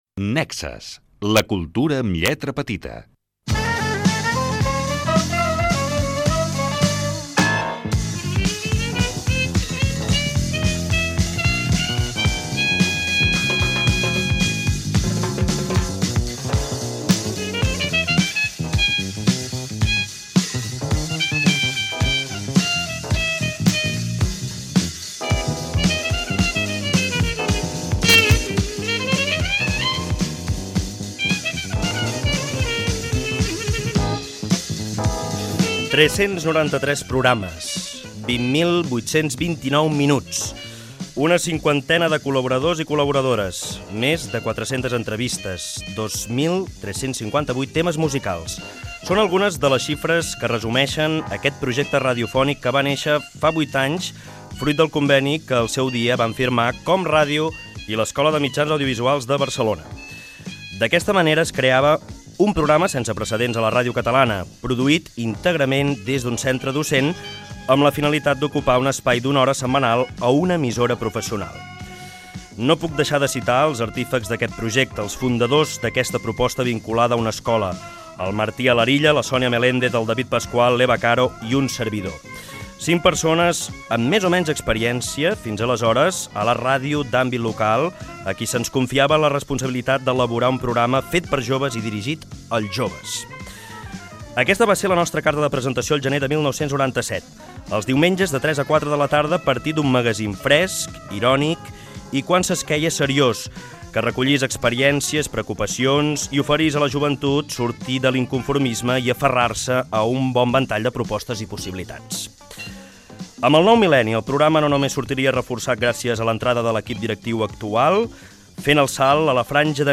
Indicatiu del programa, història i balanç dels 8 anys d'emissió del programa. L'actor Pepe Rubianes invita a escoltar el programa